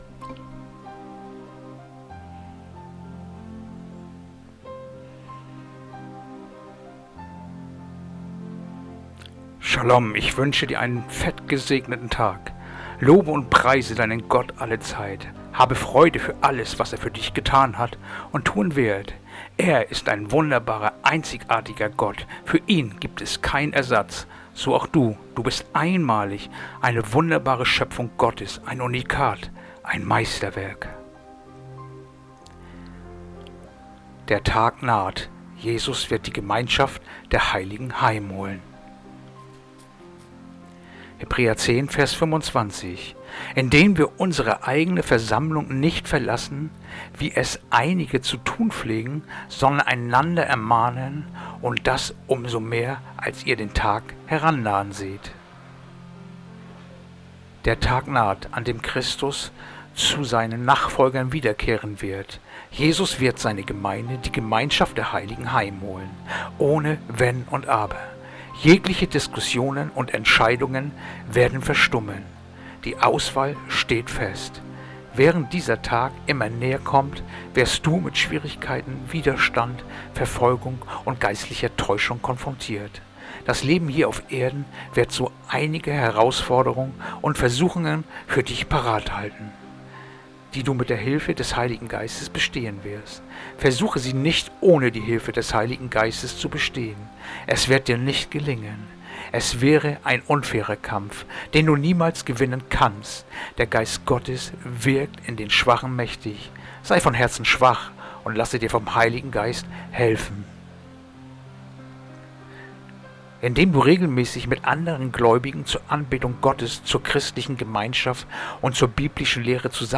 Andacht-vom-02-Juni-Hebräer-10-25